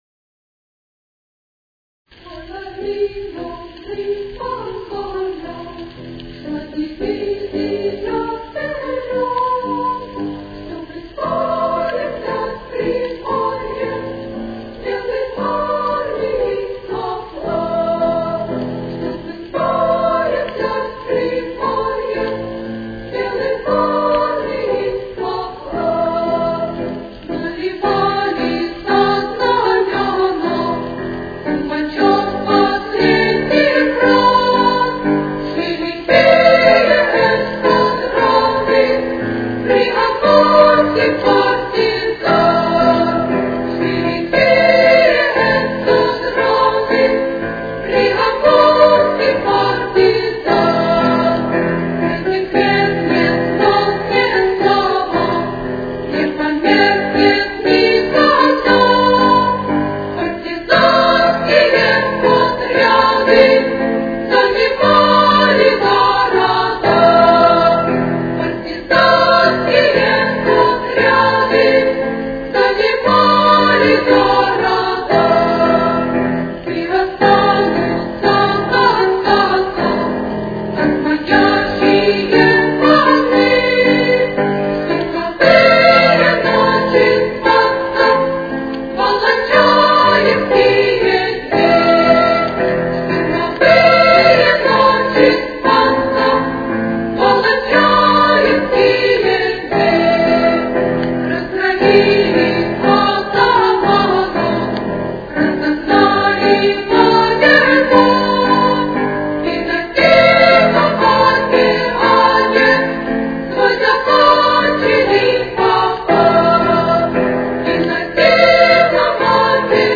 Ми минор. Темп: 119.